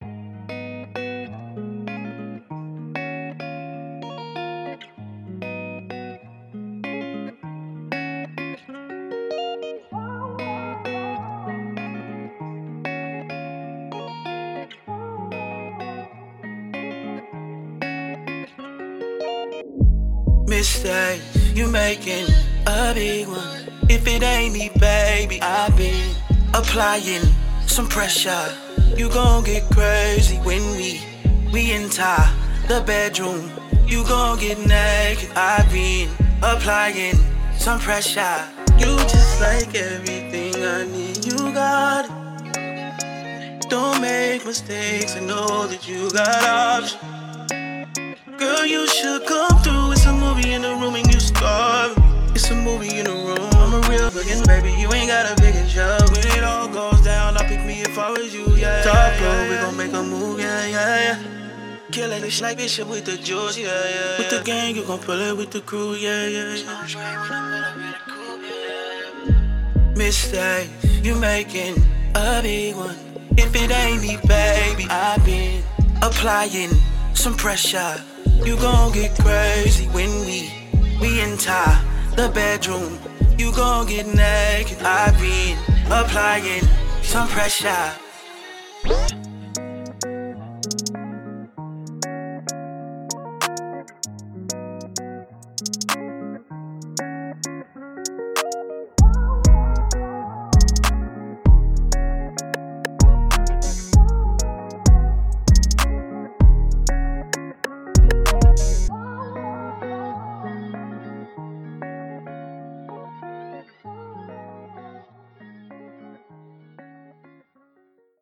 RnB
This a dope crooner from Kansas City Mo.